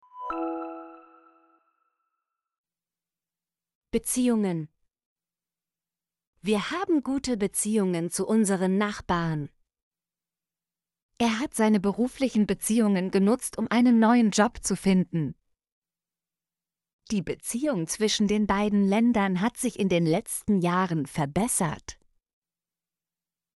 beziehungen - Example Sentences & Pronunciation, German Frequency List